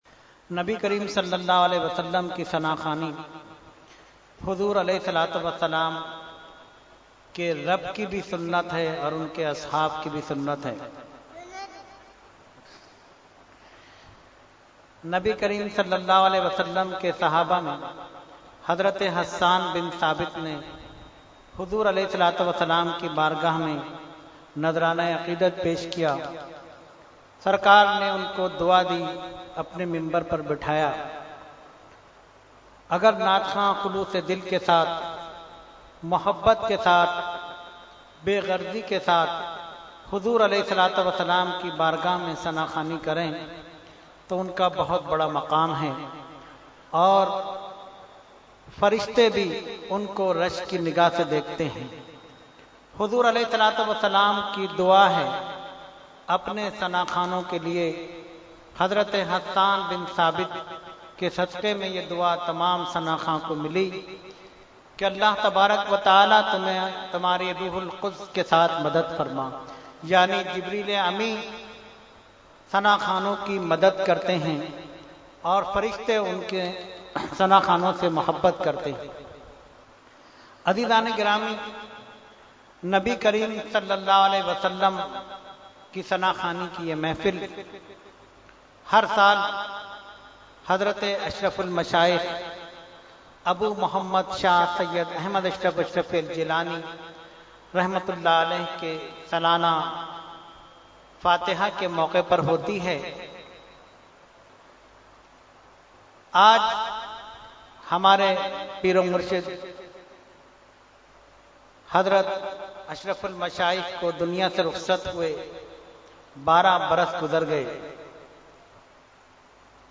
Category : Speech | Language : UrduEvent : Urs Ashraful Mashaikh 2017